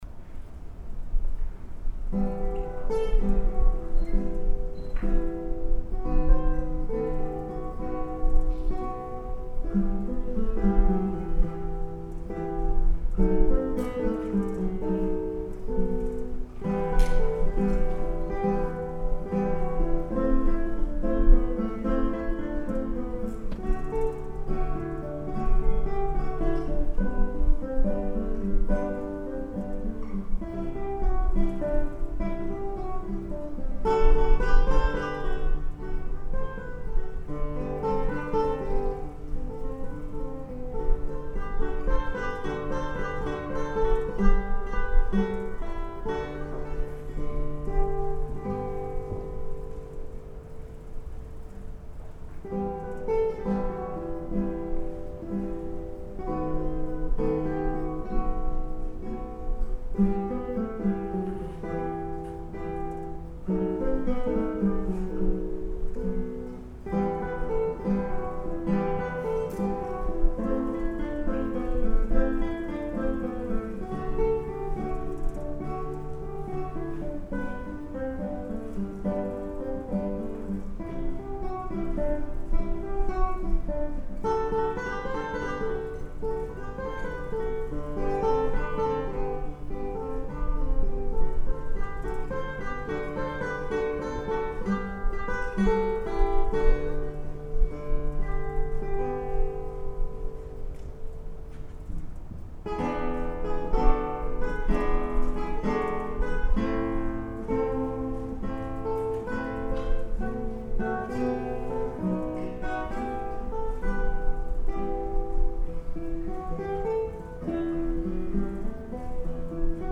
ギターコンサート
duo